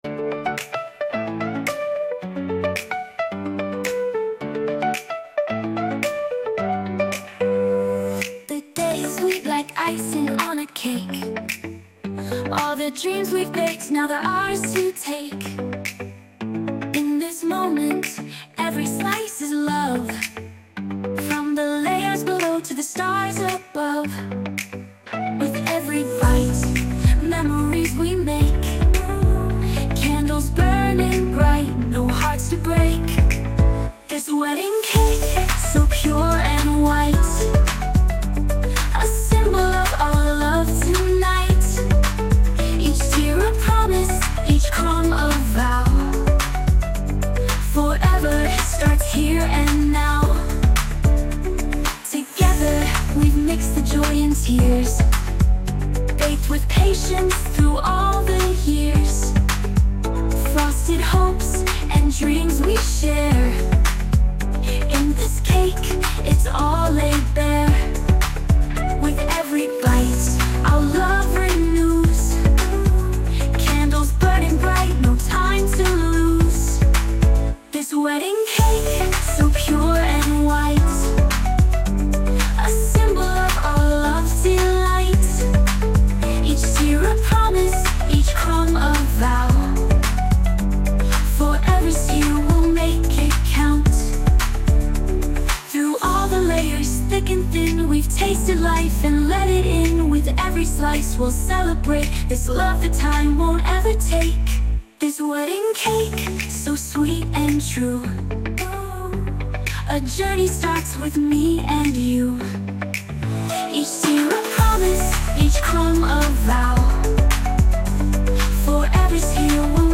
洋楽女性ボーカル著作権フリーBGM ボーカル
女性ボーカル洋楽 女性ボーカル披露宴BGM
著作権フリーのオリジナルBGMです。
女性ボーカル（洋楽・英語）曲です。